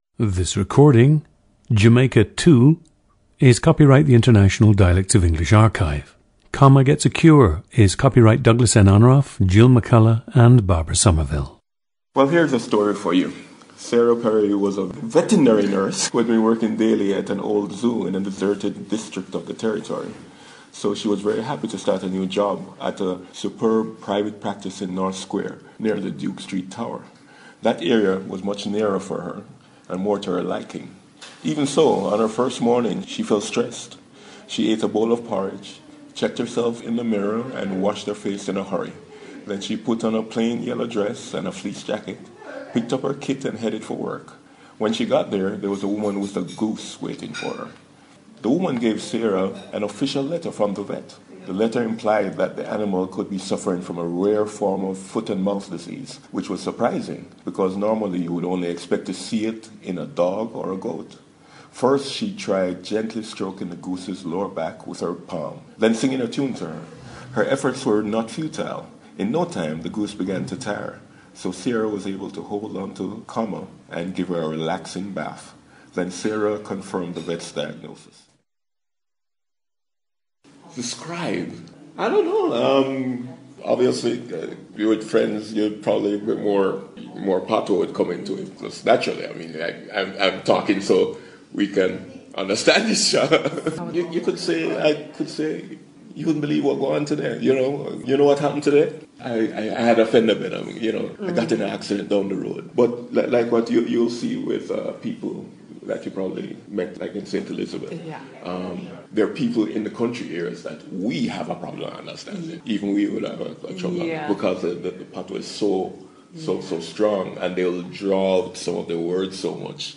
PLACE OF BIRTH: Mandeville, Jamaica
GENDER: male
The subject was born, raised and currently resides in Mandeville, Jamaica.
Subject grew up in a city.
• Recordings of accent/dialect speakers from the region you select.